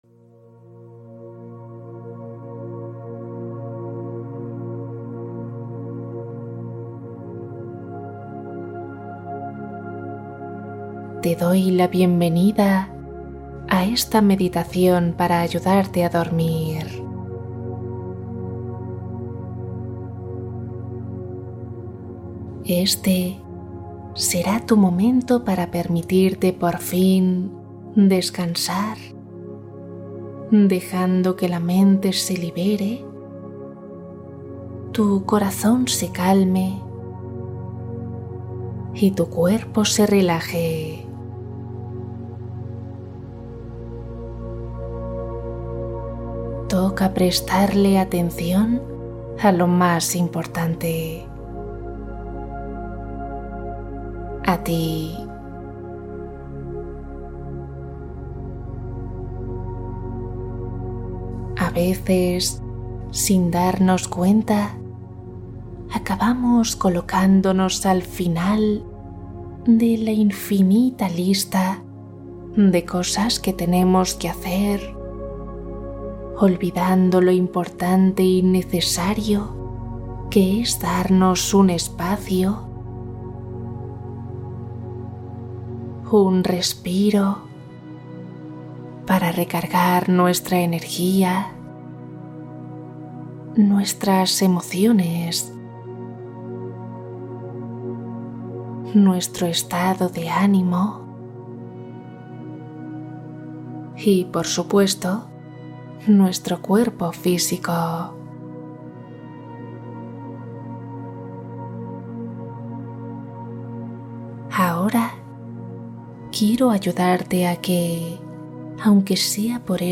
Noche de calma ♥ Cuento + meditación para una hora de descanso profundo